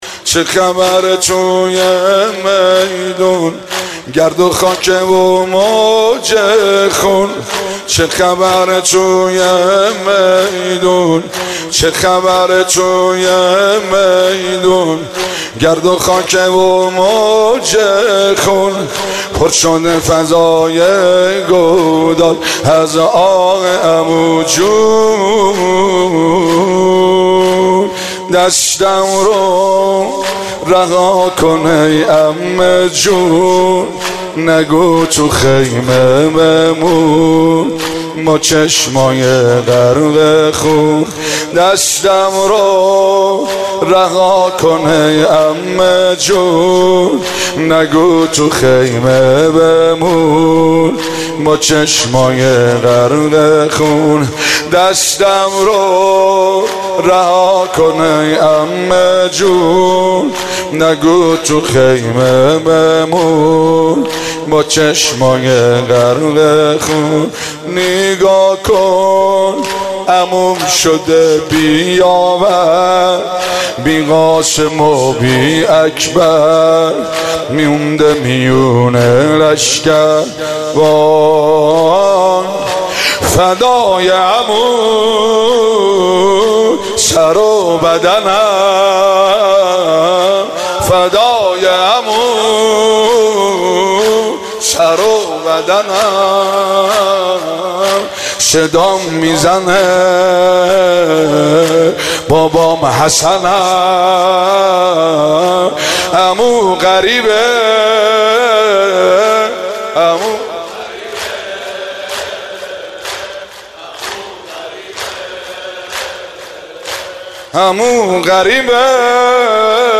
مداحی
در شب پنجم محرم 94